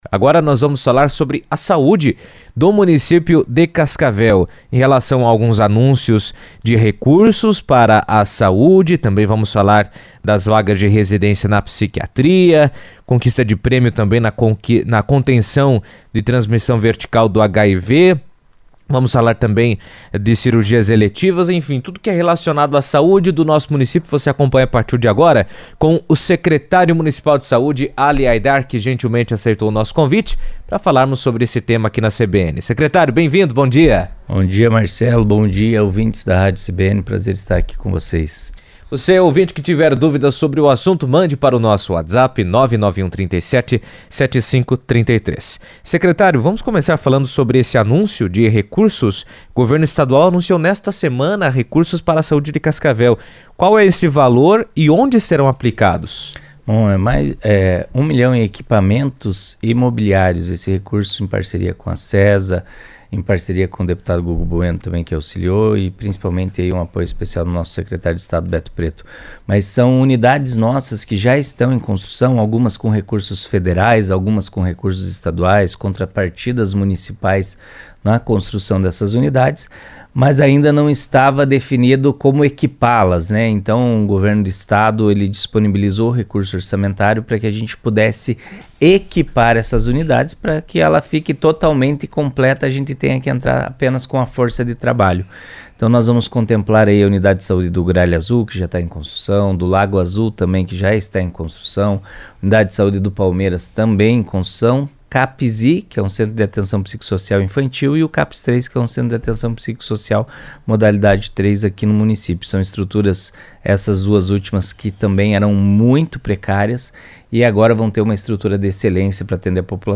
O Governo Estadual anunciou, na segunda-feira (15), um investimento de R$ 1 milhão para a saúde de Cascavel e, na mesma ocasião, foram entregues quatro novas ambulâncias ao município. Entre os avanços recentes, Cascavel também abriu vagas de residência em psiquiatria e conquistou um prêmio pela contenção da transmissão vertical do HIV, reforçando ações de prevenção e qualificação dos serviços de saúde. Apesar das conquistas, as filas de exames e cirurgias eletivas ainda representam um desafio, e o tema foi abordado pelo secretário municipal de Saúde, Ali Haidar, em entrevista à CBN, que destac